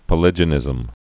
(pə-lĭjə-nĭzəm)